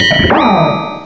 cry_not_escavalier.aif